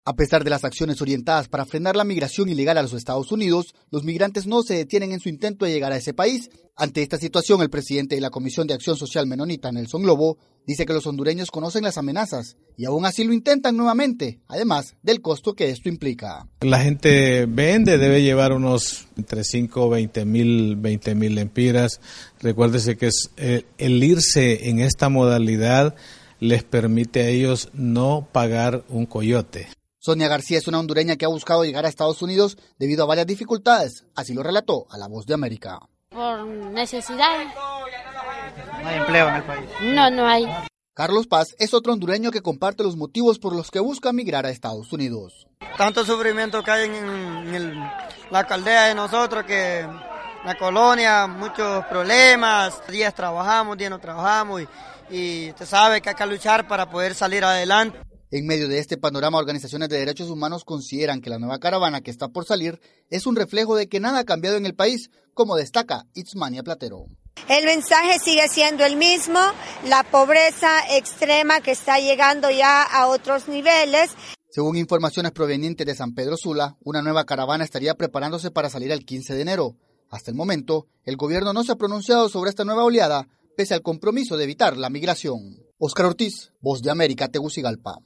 VOA: Informe de Honduras